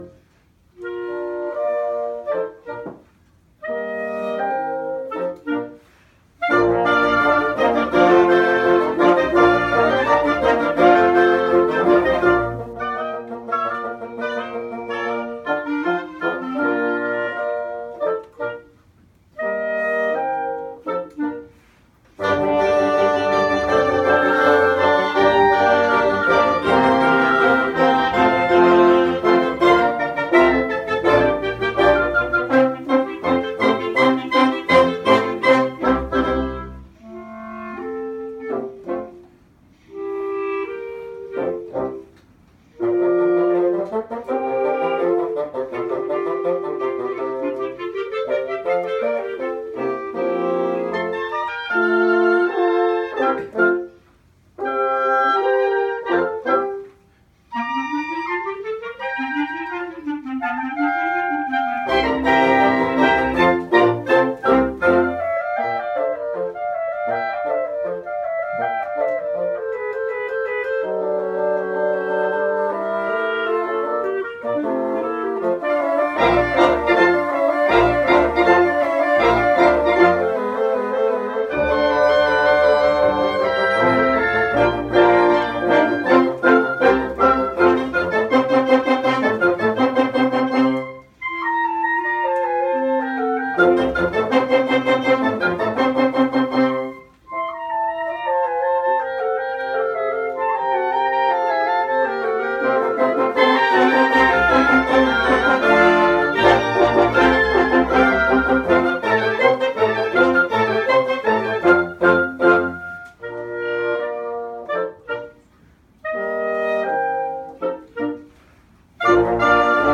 Hellgate Harmonie is a collaborative of New York City amateur and freelance wind musicians with a special interest in playing octets in public spaces.